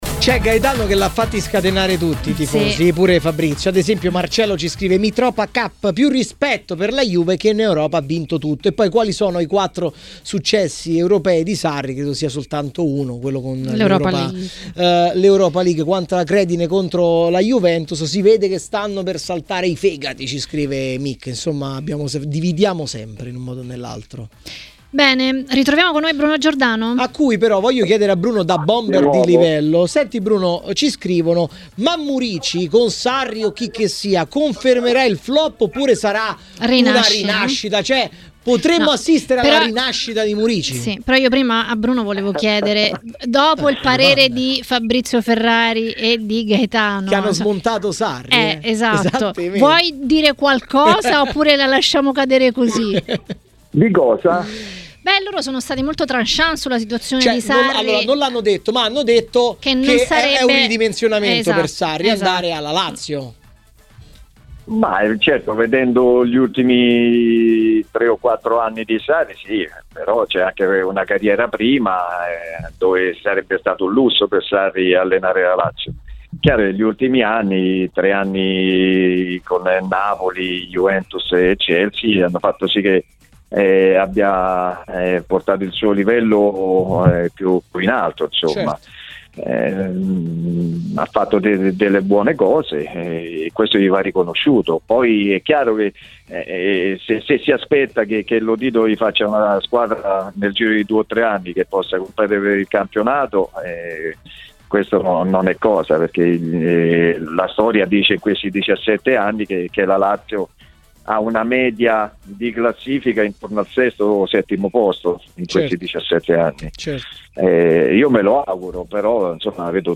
A parlare dei temi della Serie A a Maracanà, nel pomeriggio di TMW Radio, è stato l'ex calciatore e tecnico Bruno Giordano.